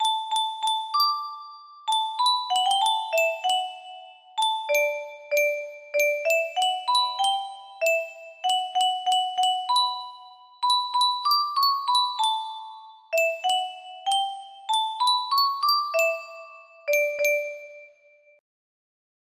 Yunsheng Music Box - School Song 3000 music box melody
Full range 60